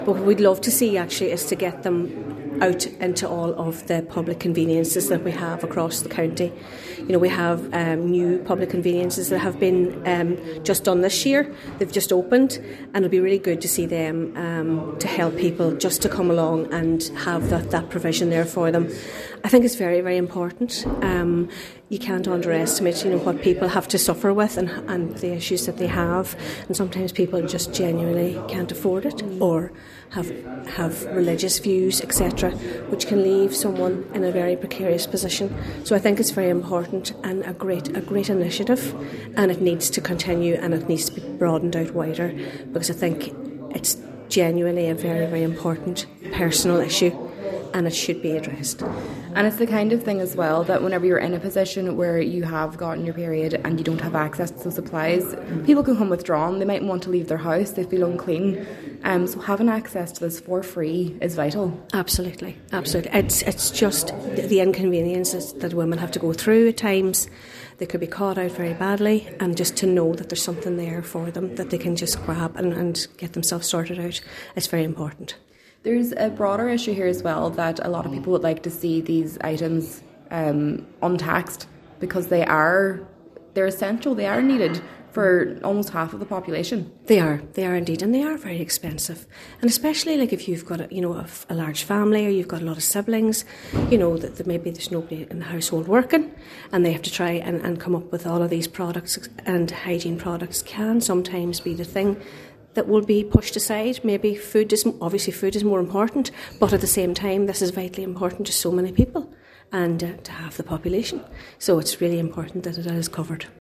Cllr Niamh Kennedy says this represents a positive step forward for women’s health, and she’d like to see it extended out further…………..